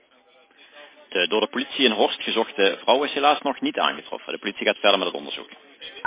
Burgernet heeft een audioboodschap ingesproken bij deze melding.